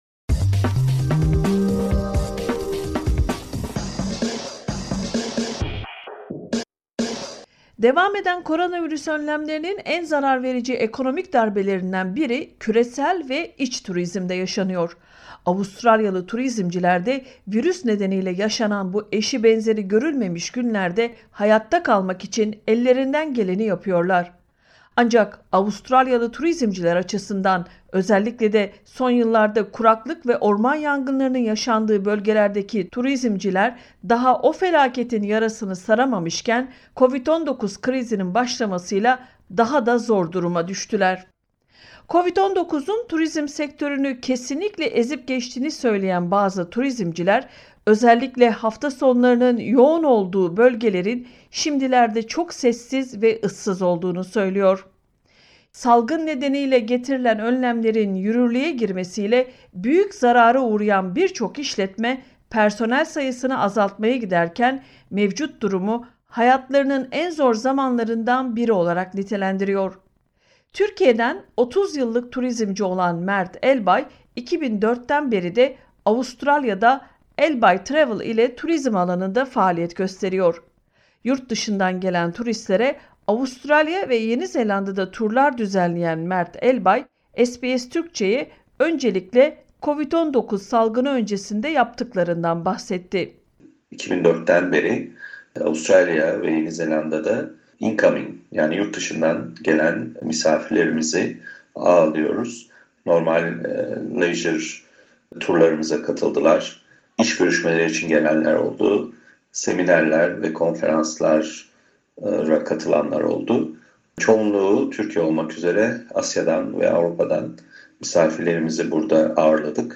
turizm_soylesisi.mp3